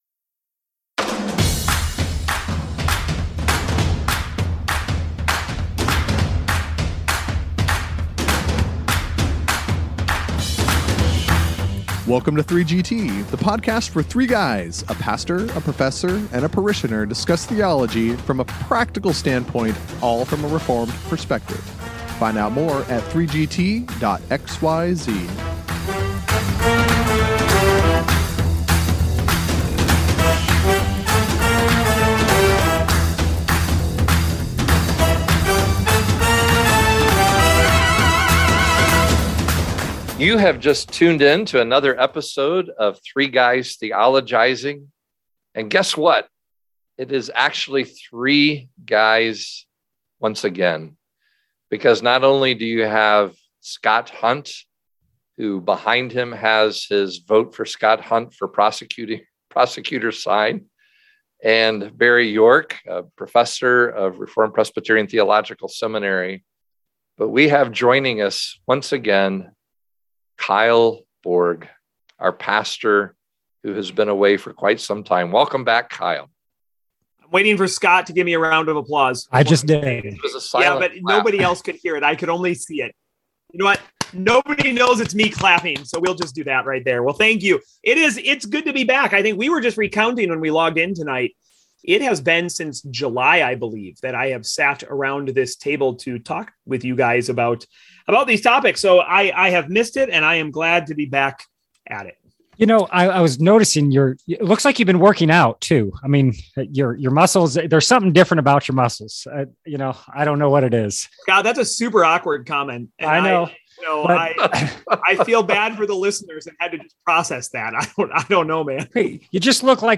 After a long hiatus, the pastor is welcomed back by the parishioner and professor on 3GT once again.
The guys offer pastoral advice on how to interact with those promoting their progressive theology.